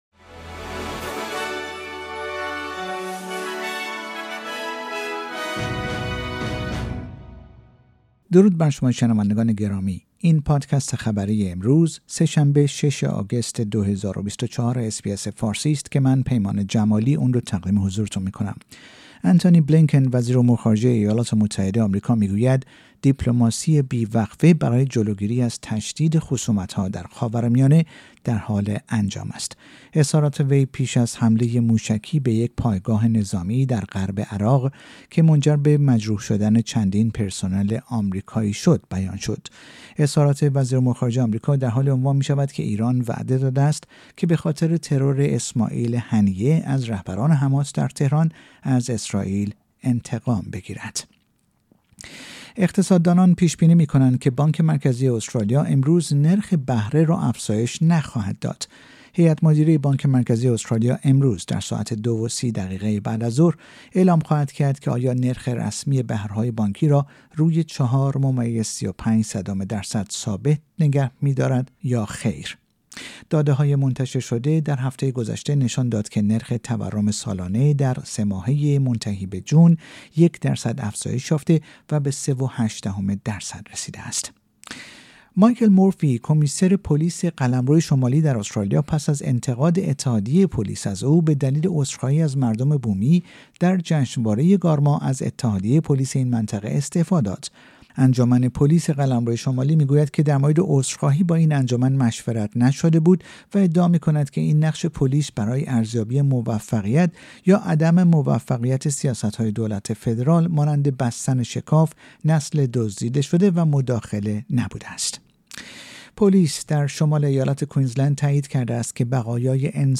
در این پادکست خبری مهمترین اخبار استرالیا در روز چهارشنبه ۷ آگوست ۲۰۲۴ ارائه شده است.